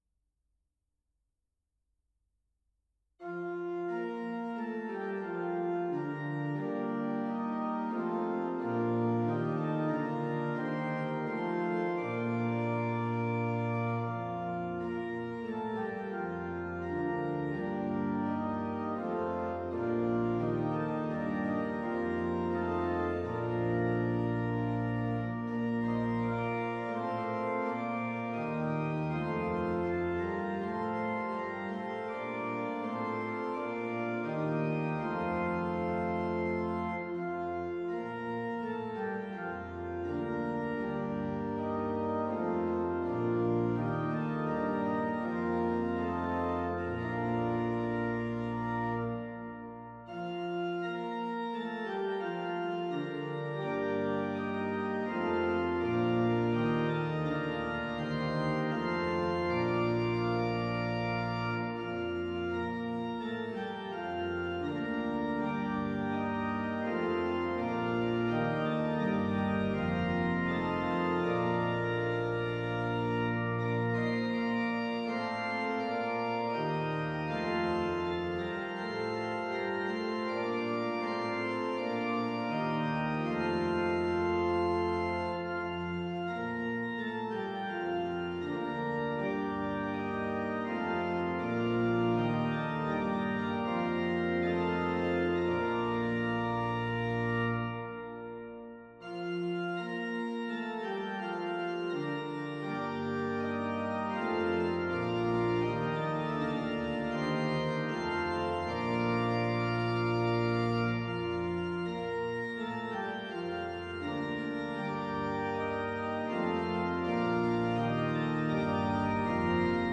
In the absence of our usual Palm Sunday Service the following scriptures, traditional Palm Sunday hymns of celebration and their words are being provided for your worship.